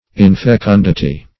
infecundity - definition of infecundity - synonyms, pronunciation, spelling from Free Dictionary
Search Result for " infecundity" : The Collaborative International Dictionary of English v.0.48: Infecundity \In`fe*cun"di*ty\, n. [L. infecunditas: cf. F. inf['e]condit['e].]